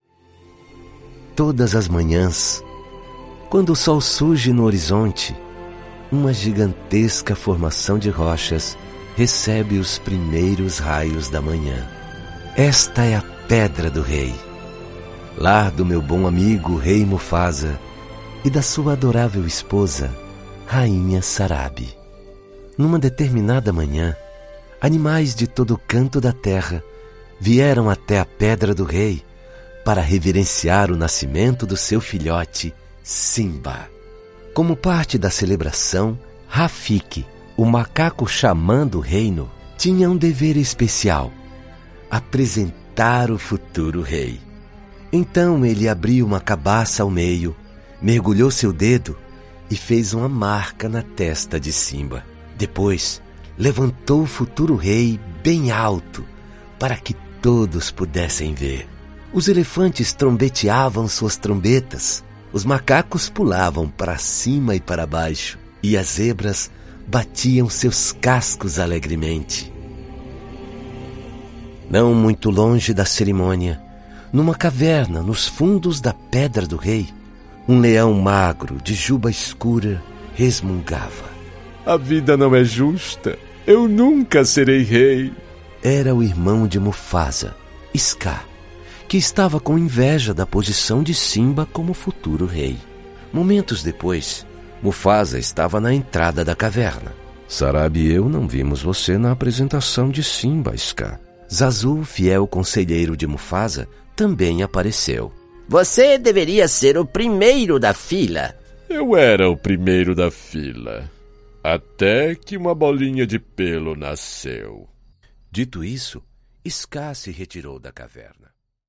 offering a confident, versatile baritone voice
Narration
Neumann tlm103, Audio Technica AT 4033, Avalon vt737SP, Audient Id14, Yamaha HS50, Mac Mini M1